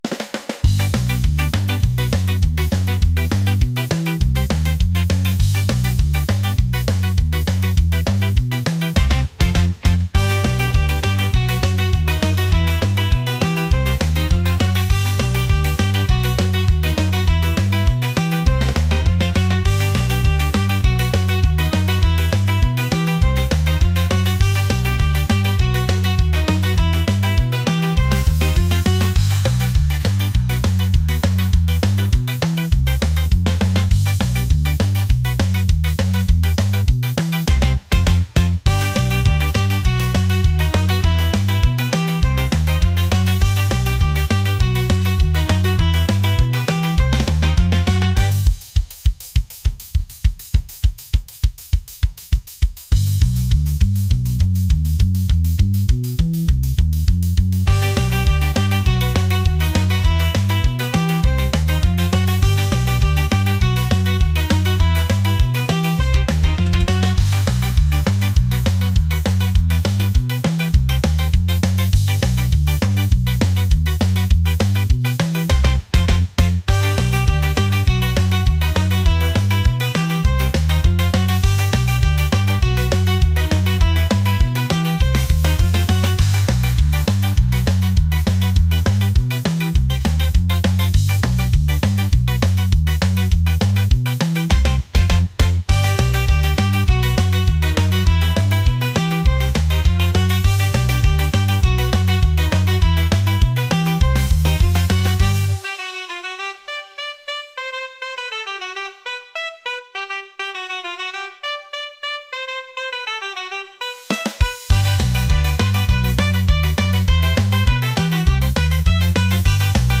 ska | energetic | upbeat